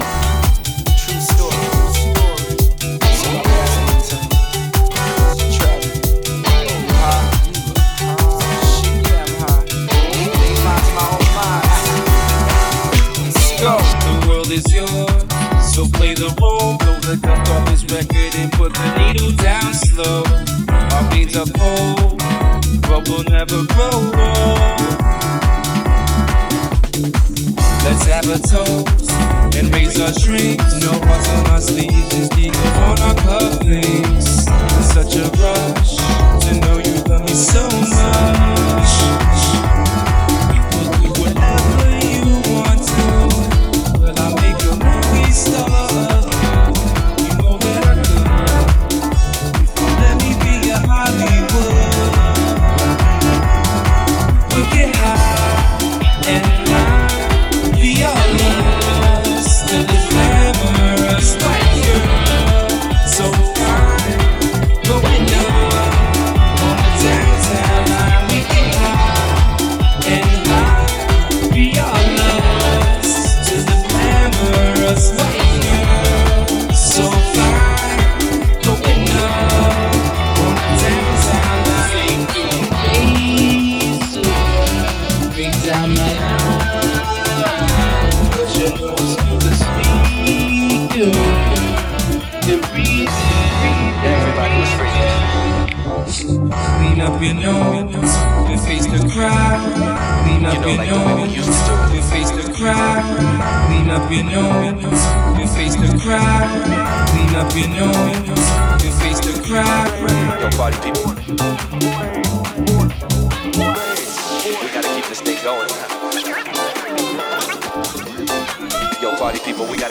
Genre: EDM.